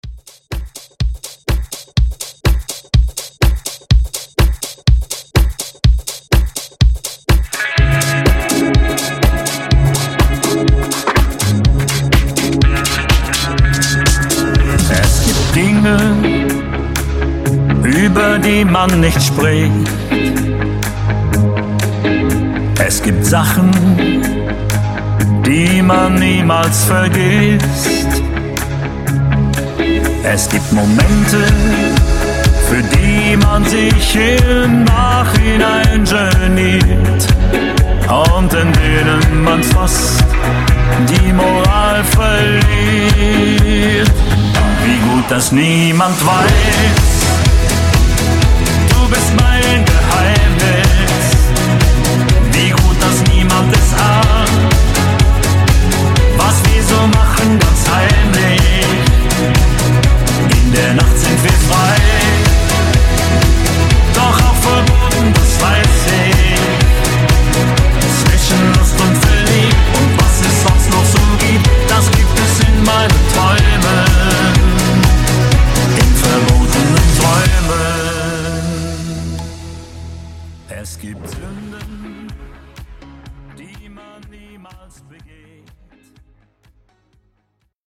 Genres: DANCE , RE-DRUM , TOP40 Version: Dirty BPM: 140 Time